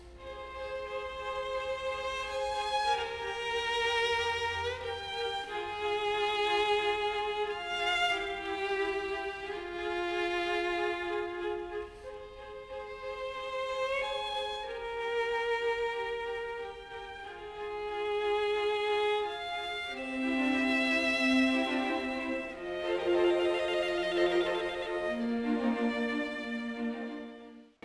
The upper and lower strings alternately playing ascending and descending arpeggios in answer to the soloists scales.
The third solo section is again marked 'Drunkard', and shows off the soloists virtuoso skills with semiquaver arpeggios and demi-semiquaver/hemi-demi-semiquaver runs.
The music is marked 'p e larghetto' - quiet and broad, and has a sudden switch of key to F minor.